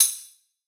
soft-slidertick.wav